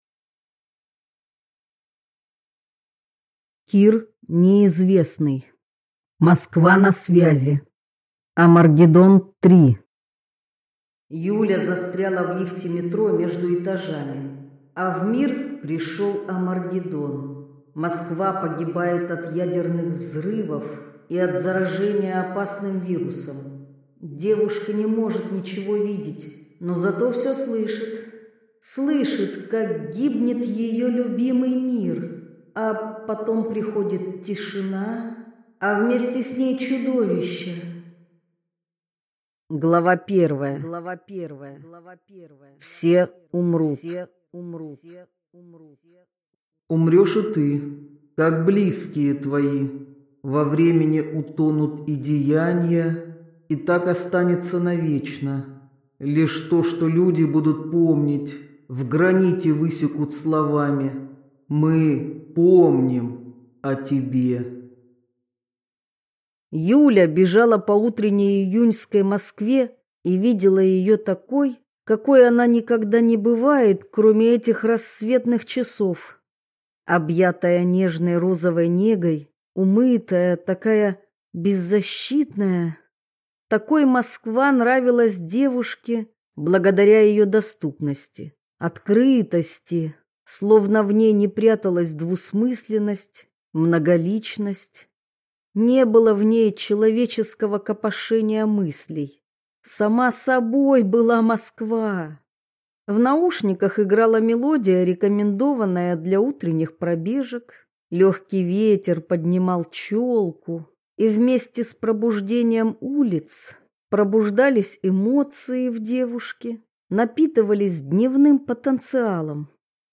Аудиокнига Москва на связи | Библиотека аудиокниг